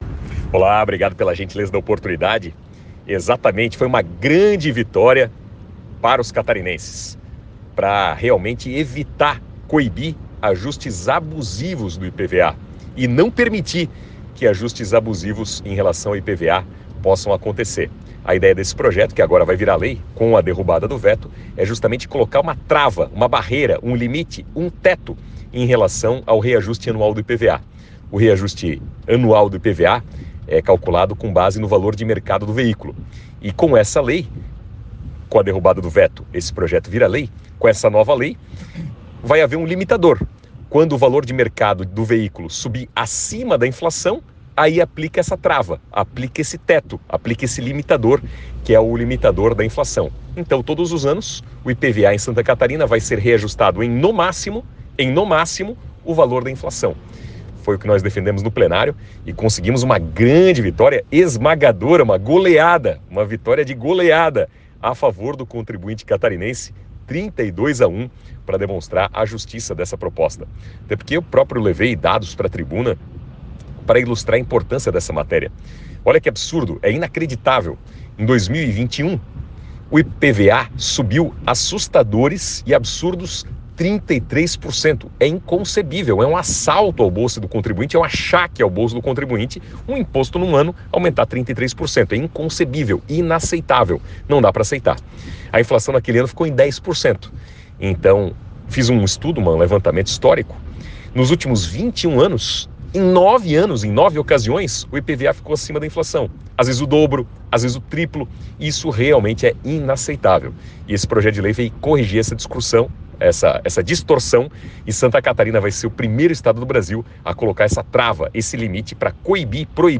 Áudio do deputado Napoleão Bernardes